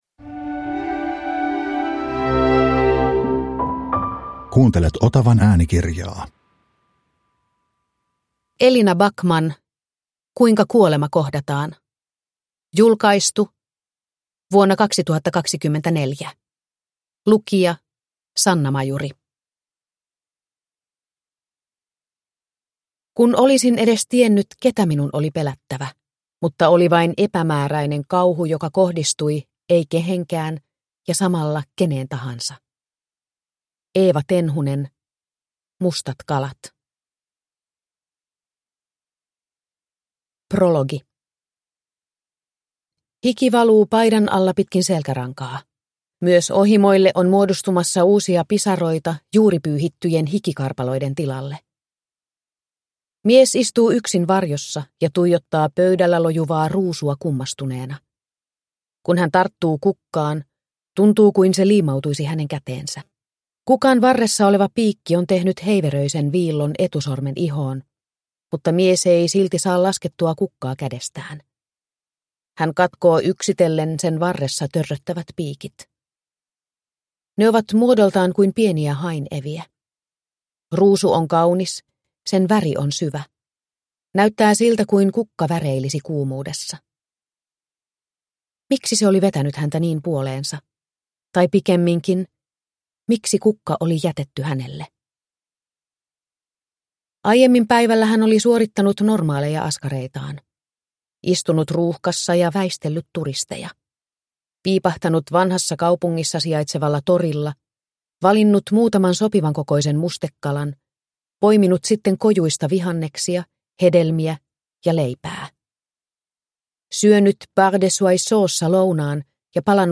Kuinka kuolema kohdataan (ljudbok) av Elina Backman